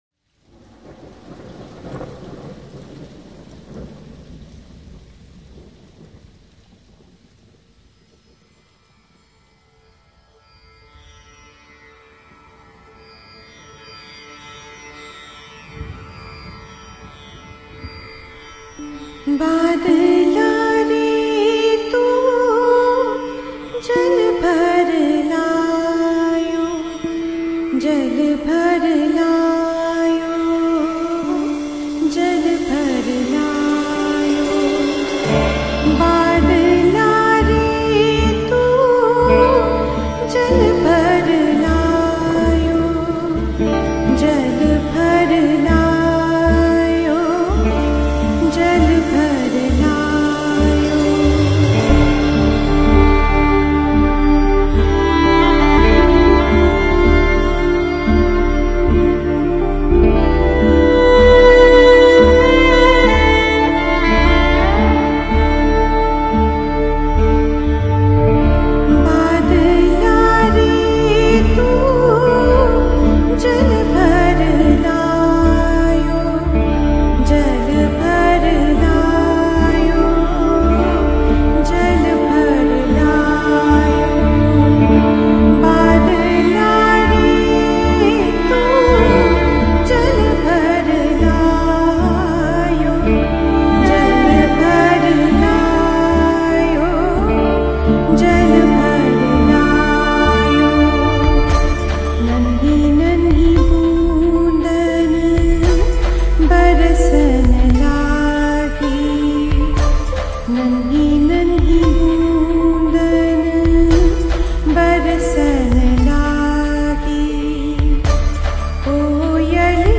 Vocals
Guitar, Bass, Keyboards, Programming
Violin